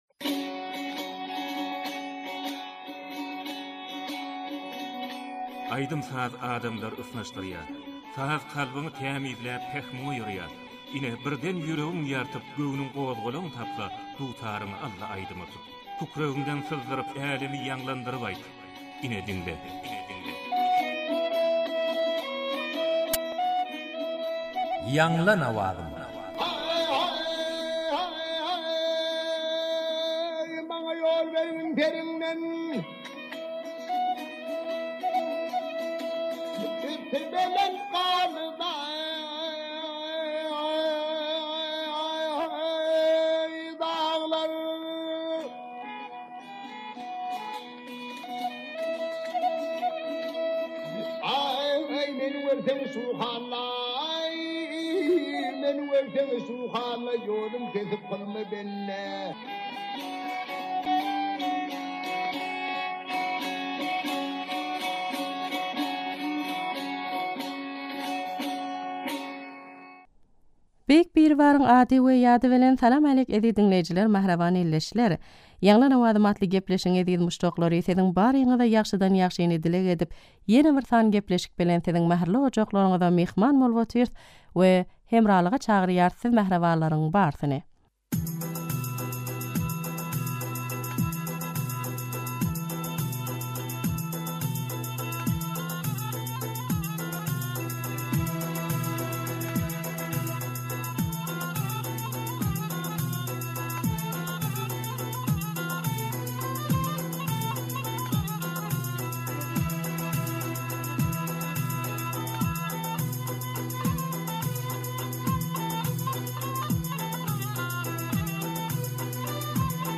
turkmen owaz aýdym